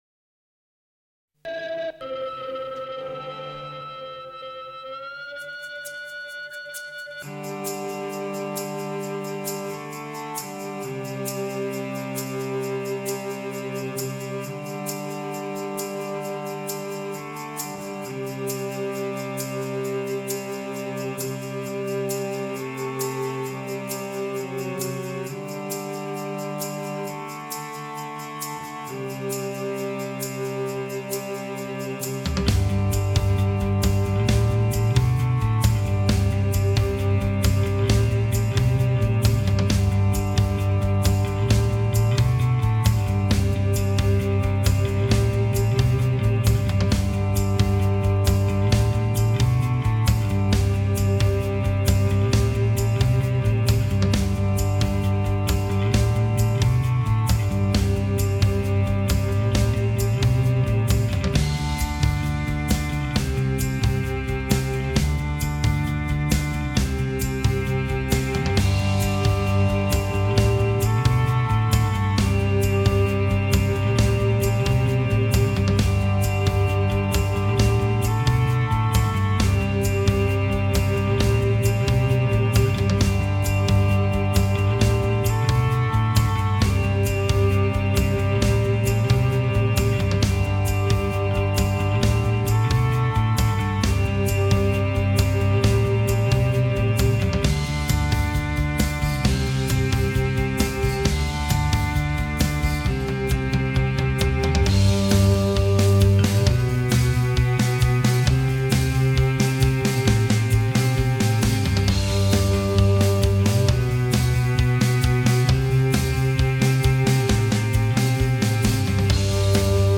BPM : 132
Tuning : E
Without vocals